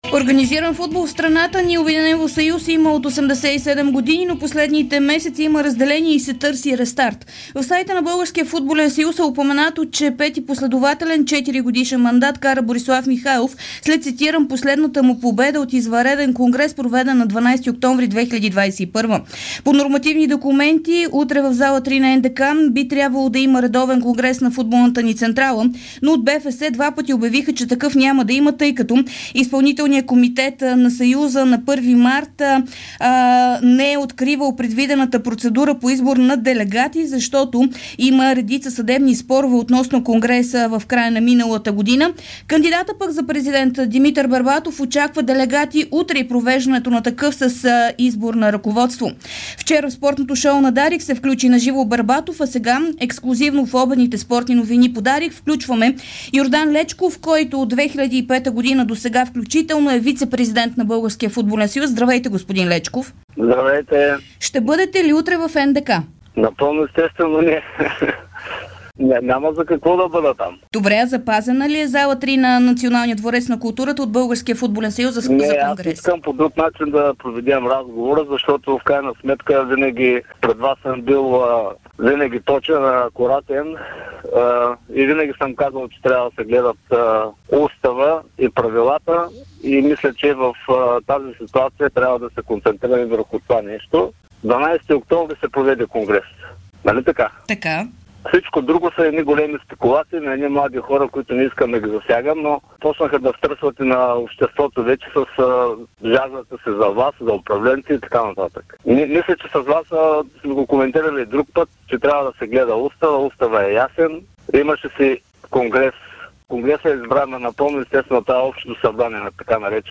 Вицепрезидентът на БФС и член на Изпълкома на футболната централа Йордан Лечков даде ексклузивно интервю за Дарик радио относно ситуацията с Конгреса на БФС – ще има или не и думите на Димитър Бербатов, че в петък ще се яви в НДК.